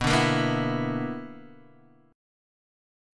BmM7bb5 chord